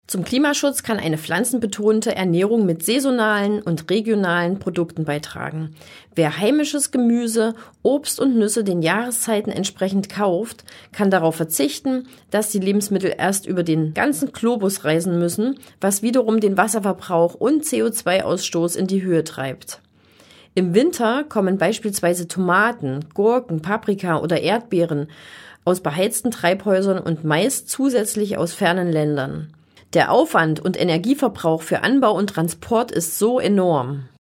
O-Töne